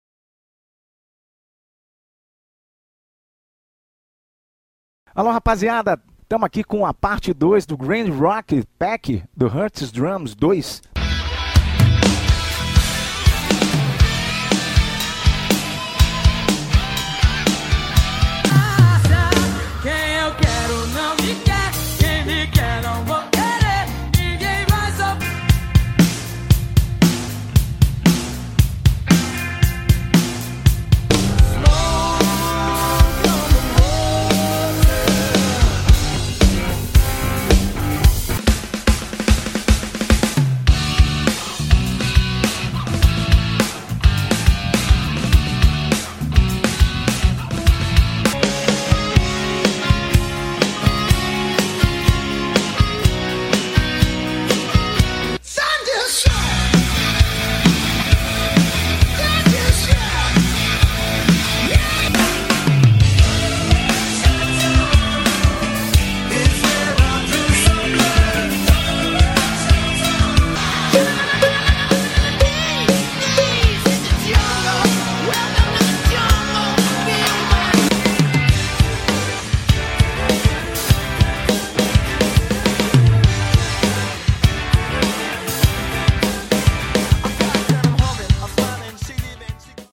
É muito som de bateria!!!
Just watch and learn more about this expansion and this VST. It’s a lot of drum sound!!!